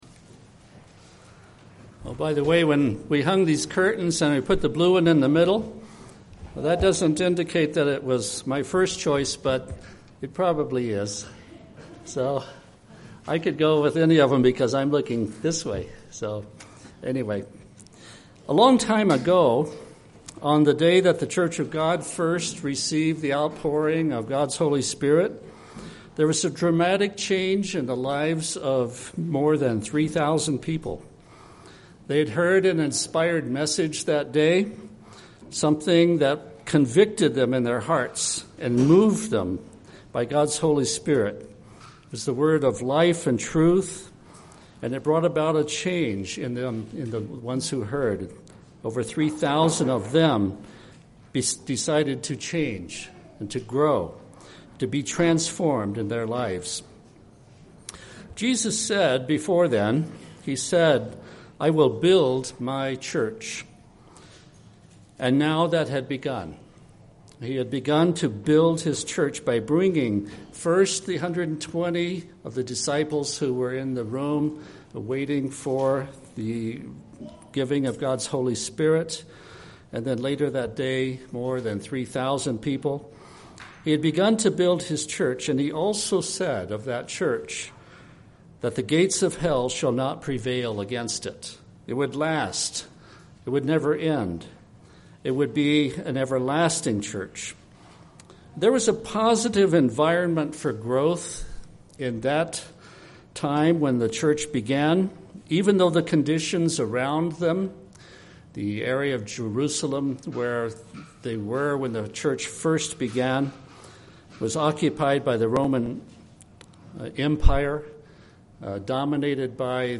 This sermon addresses seven areas in which an environment of growth can be enhanced in local congregations.
Given in Las Vegas, NV